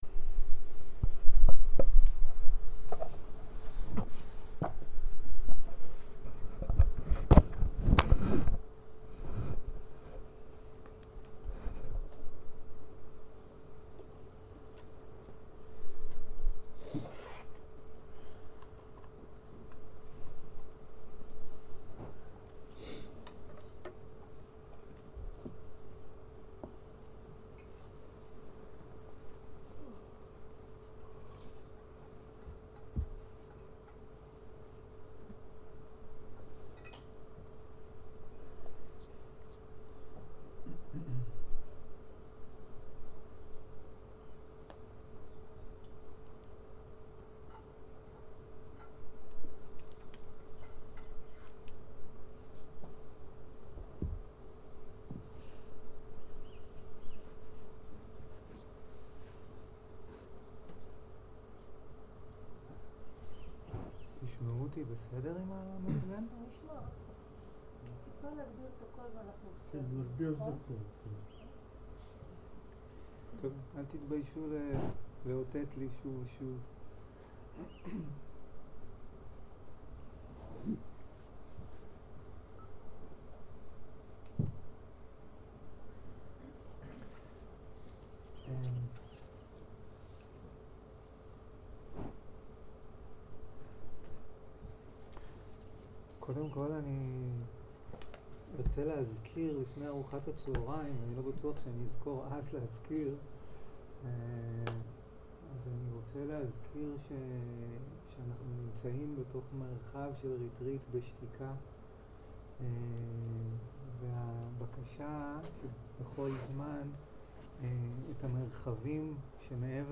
יום 2 - בוקר - שיחת דהרמה - הקלטה 2.
0:00 0:00 סוג ההקלטה: סוג ההקלטה: שיחות דהרמה שפת ההקלטה: שפת ההקלטה: עברית